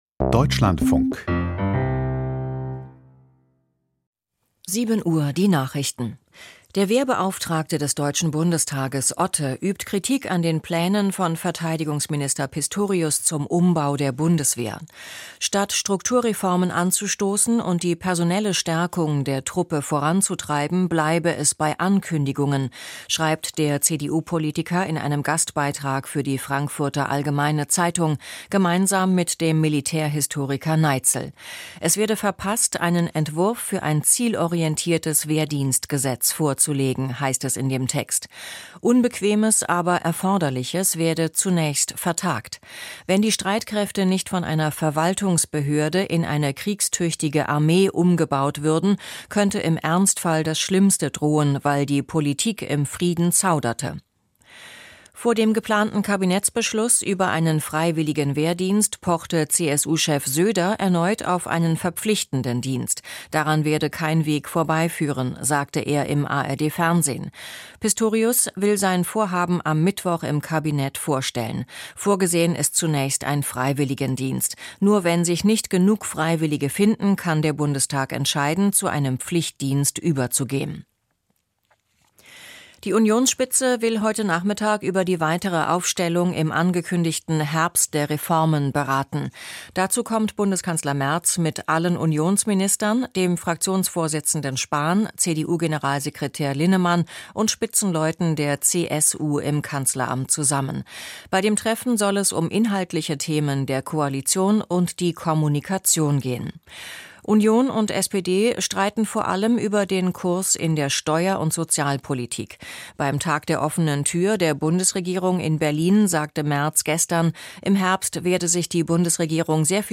Die Nachrichten vom 25.08.2025, 07:00 Uhr